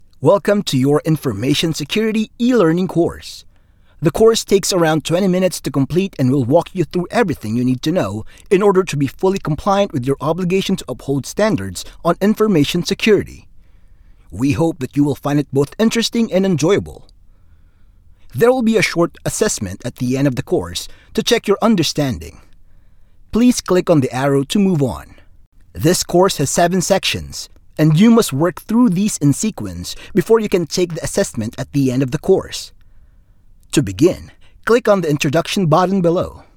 PH ENGLISH MALE VOICES
male